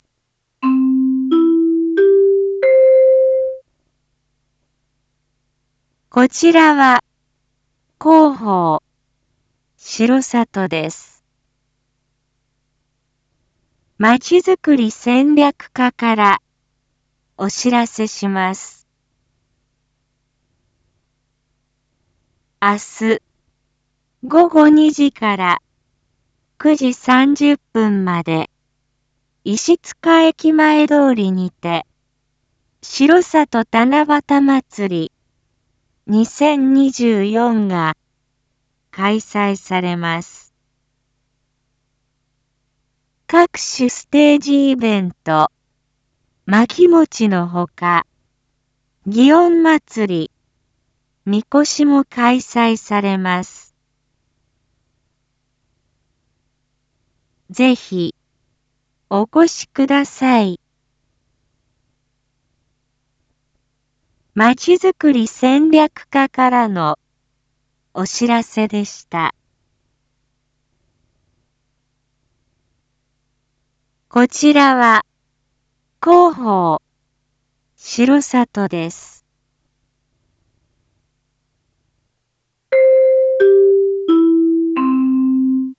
Back Home 一般放送情報 音声放送 再生 一般放送情報 登録日時：2024-07-26 19:01:27 タイトル：しろさと七夕まつり2024開催のお知らせ③ インフォメーション：こちらは広報しろさとです。